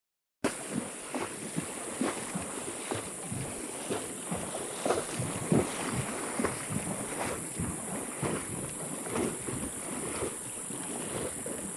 Cabalgando en el rio HEREDIA